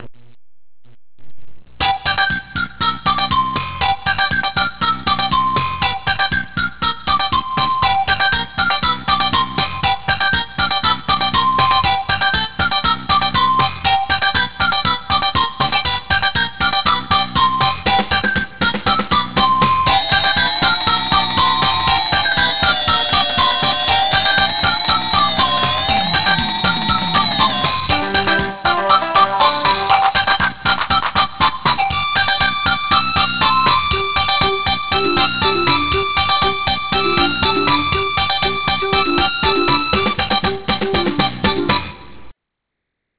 Today's Funky loop!
jamming.ra